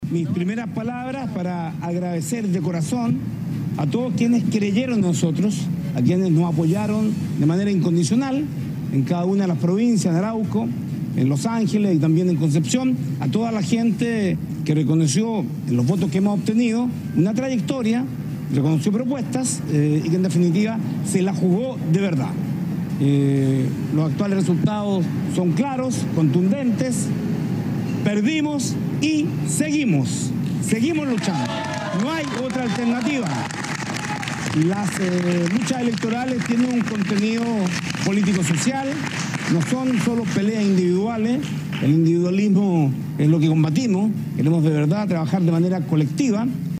Apenas unos minutos antes, el candidato Alejandro Navarro también habló con la prensa y agradeció a quienes creyeron en su programa y sostuvo que “las luchas electorales tienen un contenido político social” y espera seguir trabajando de forma colectiva.